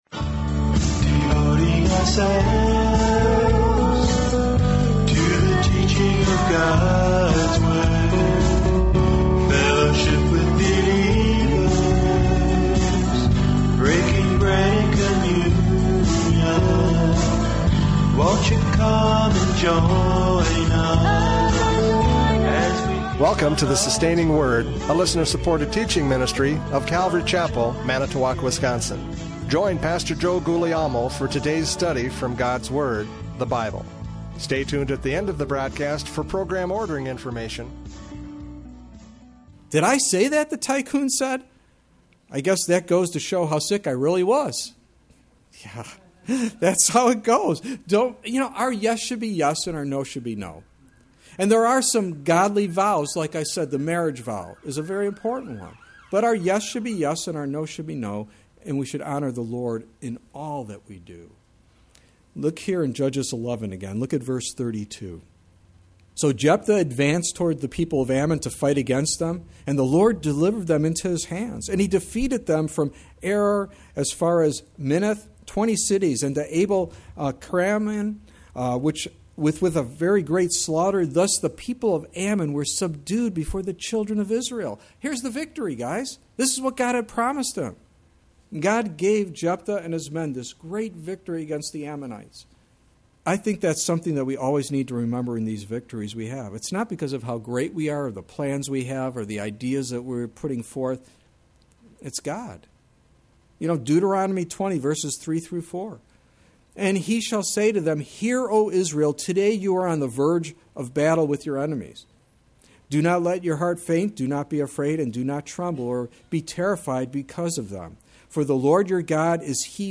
Judges 11:29-40 Service Type: Radio Programs « Judges 11:29-40 A Foolish Vow!